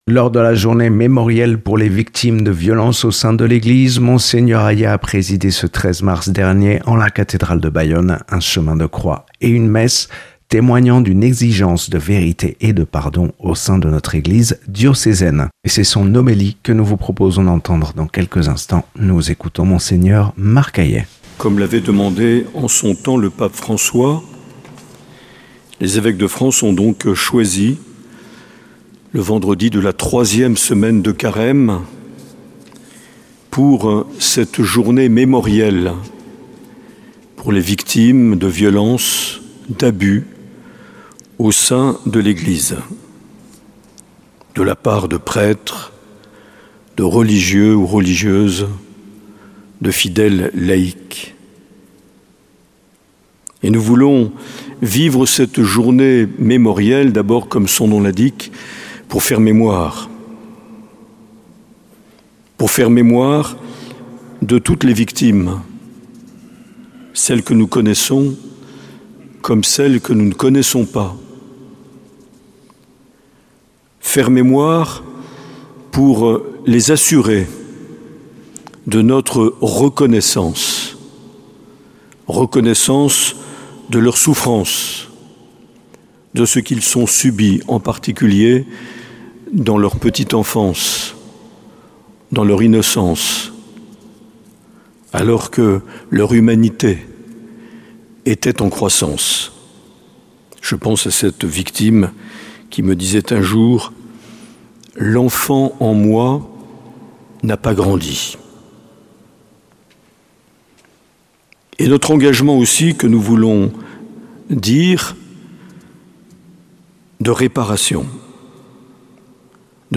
13 mars 2026 : Journée mémorielle - Cathédrale de Bayonne